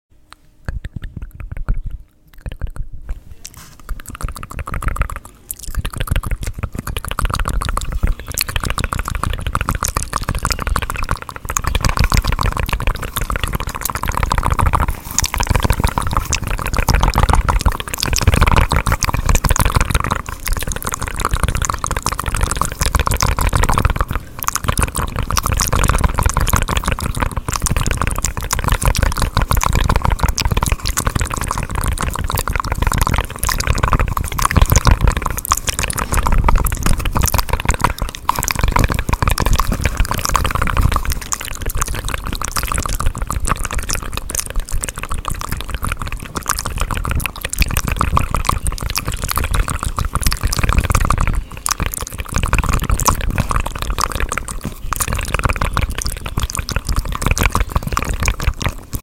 Relaxing Layered Mouth Sounds for sound effects free download
Layered mouth sounds just for you… can you handle the tingles?
Fast and agressive asmr.